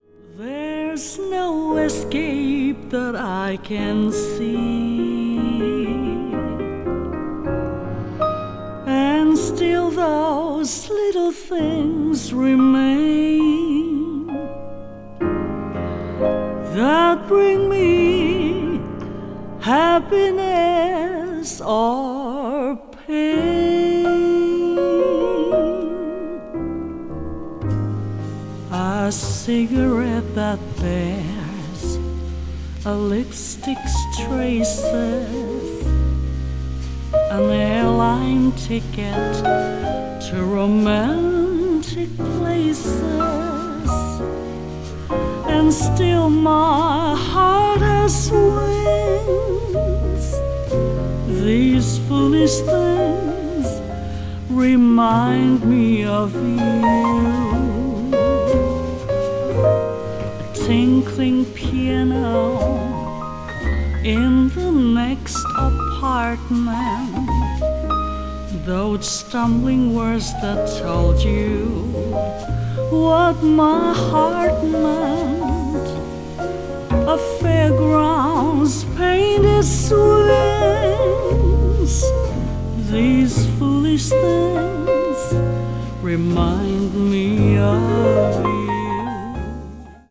media : EX+/EX+(some slightly noise.)
Japanese jazz singer
japanese jazz   jazz standard   jazz vocal